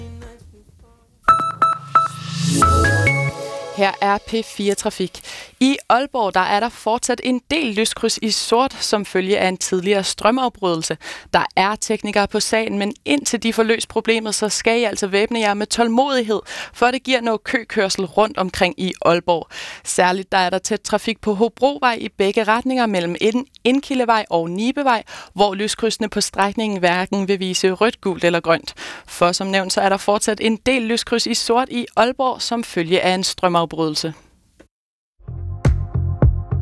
Radiomeldinger, P4
Trafikmelding, P4 Nordjylland, den 25. marts 2023: